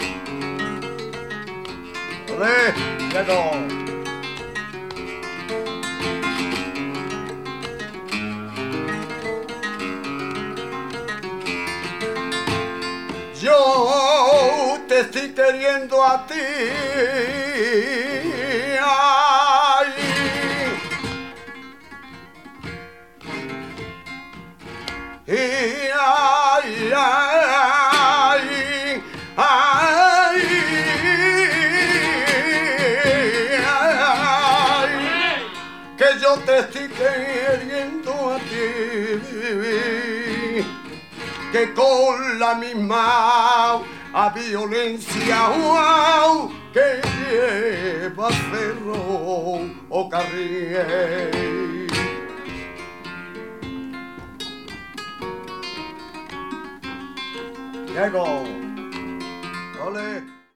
Francisco Amaya - Luis Torres Joselero / Diego de Morón
Soleá de Francisco Amaya